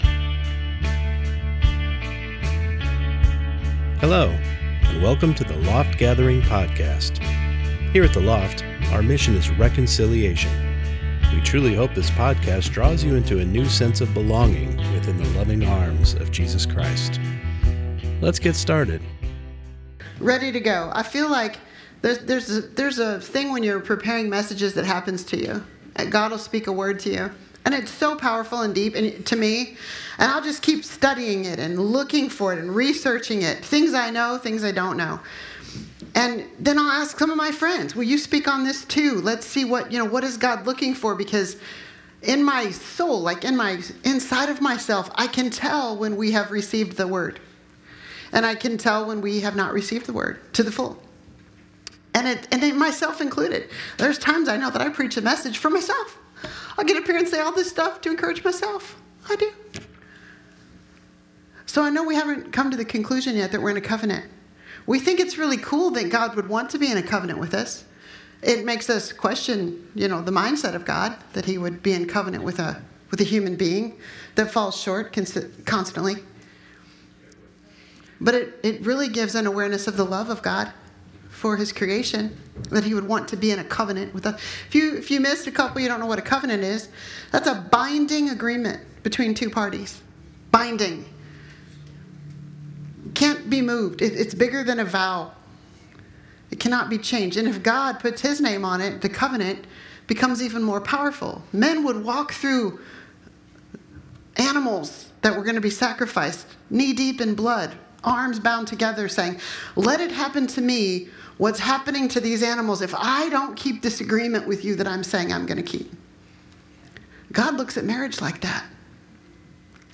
Service: Sunday Morning Service